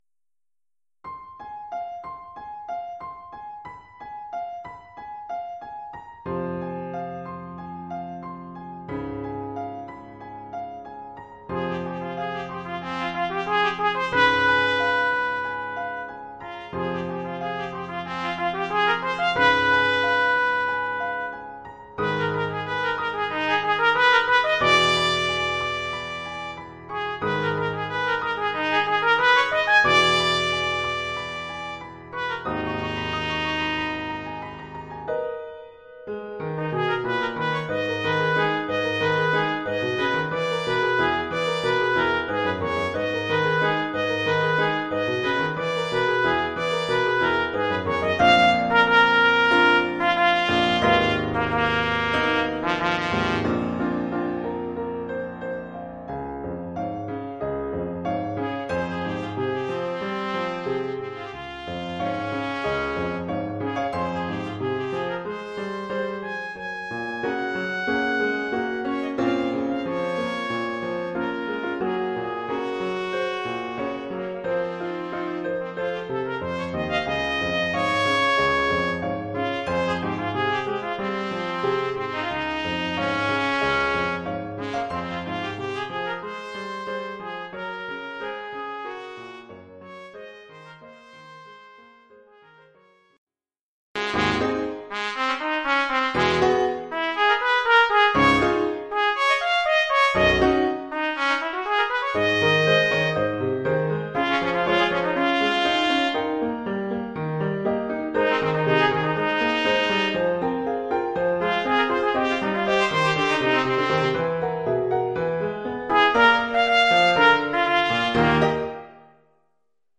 Oeuvre pour trompette ou cornet
ou bugle et piano..